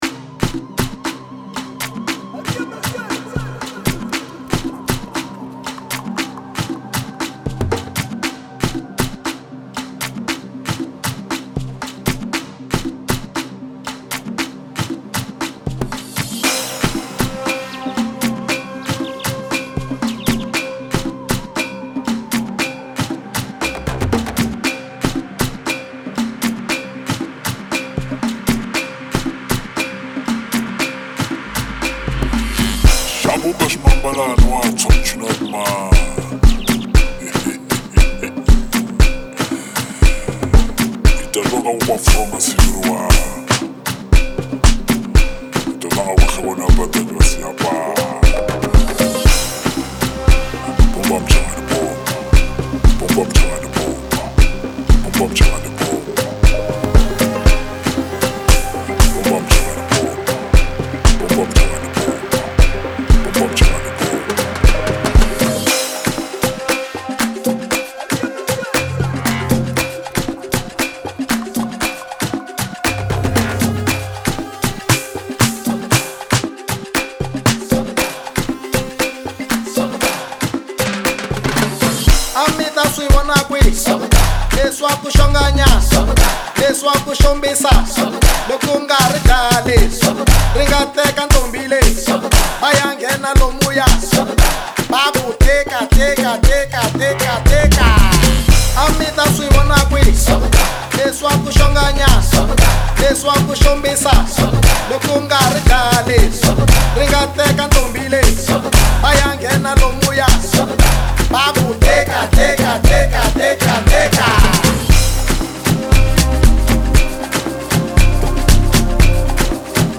Categoria: Amapiano